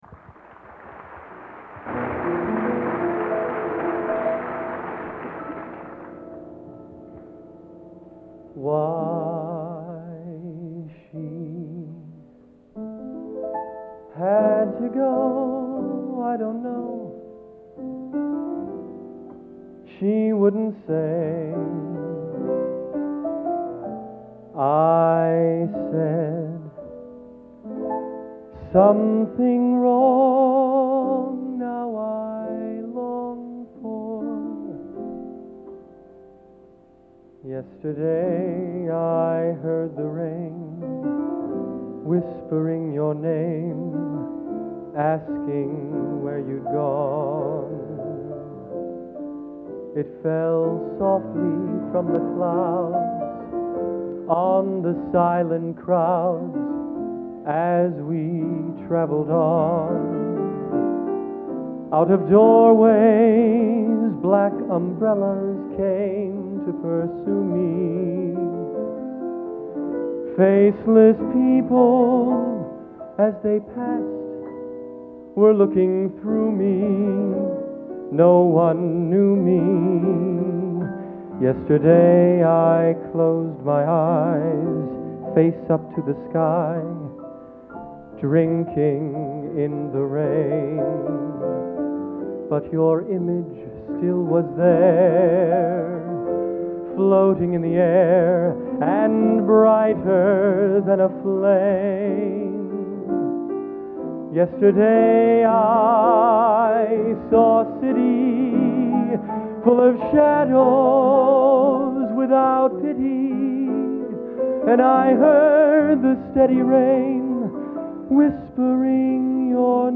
Location: West Lafayette, Indiana
Genre: | Type: End of Season
My piano arrangement to his idea.